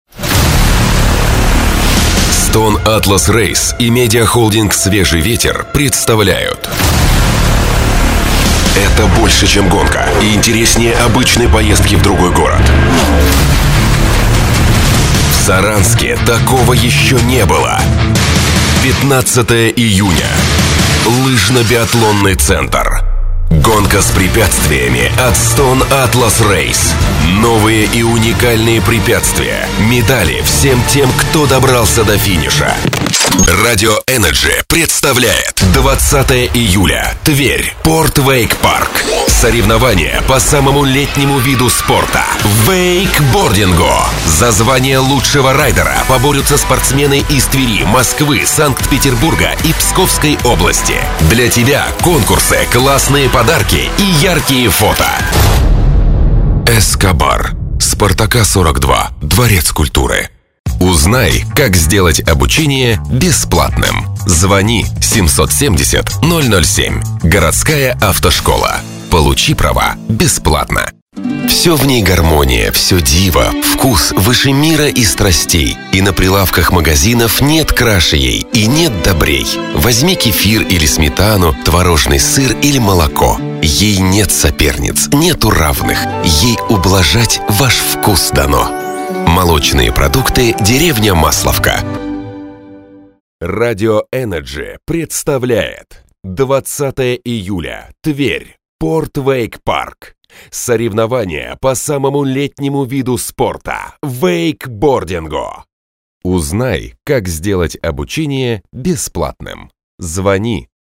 Тракт: Микрофон - Shure SM7B Пульт - Yamaha MX12/4 Карта - M-Audio Delta 10/10lt